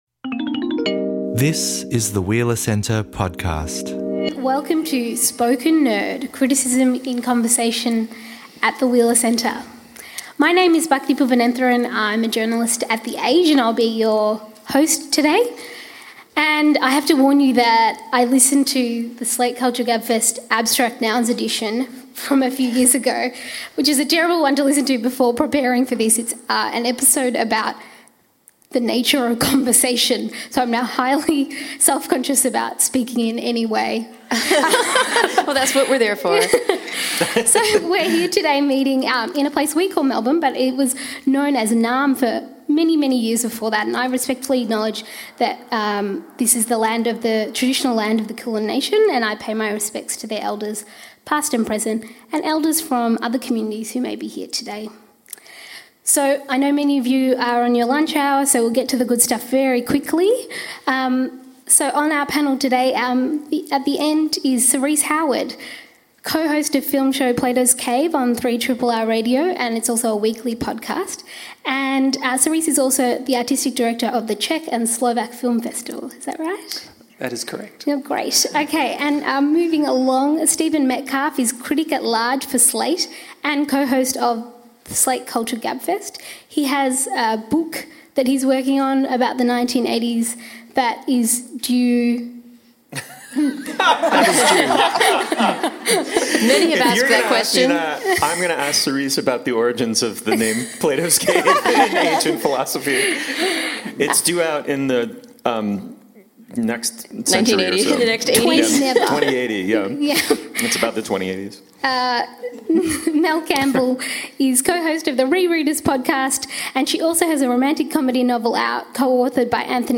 Our panelists – who work across podcast, radio and prose – will weigh up the differences between conversational criticism versus the traditional model of the solo, written perspective.